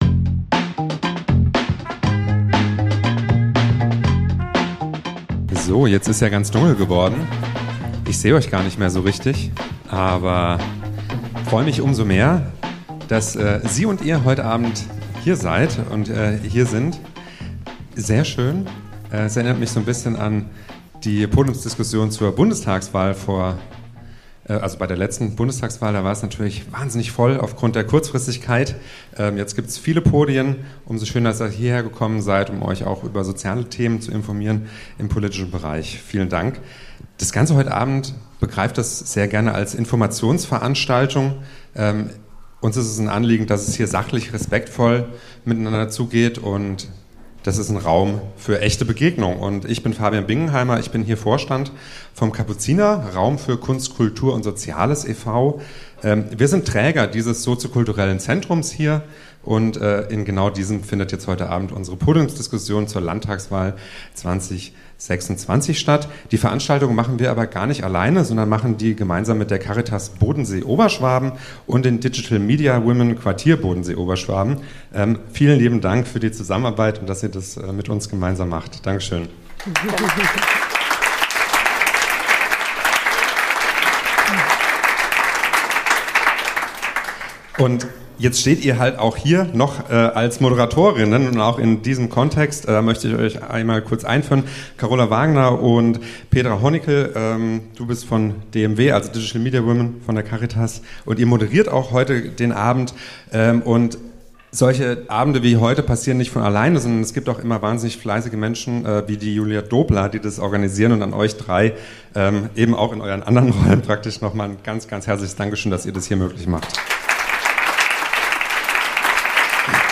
BaWü Landtagswahl 2026 - Podiumsdiskussion
Live aus dem Kapuziner vom 25.02.2026